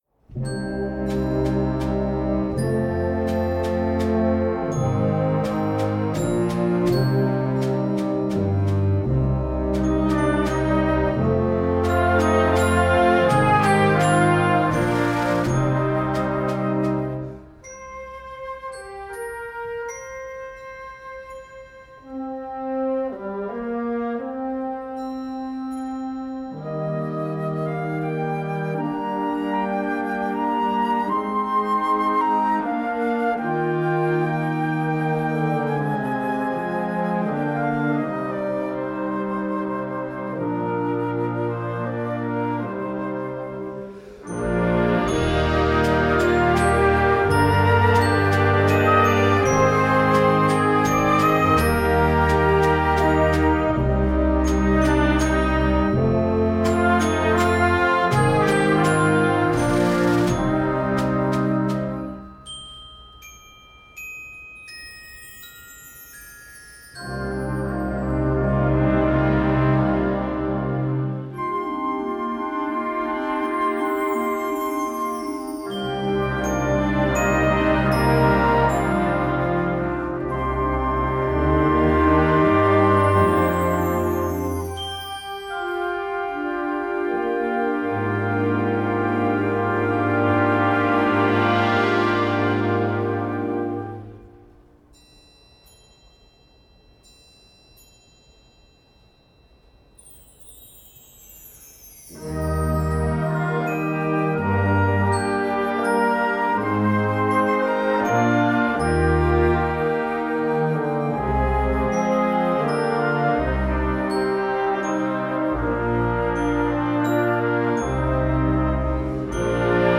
Gattung: Konzertwerk für Jugendblasorchester
Besetzung: Blasorchester
Mit traumähnlichen Harmonien und bogenförmigen Melodien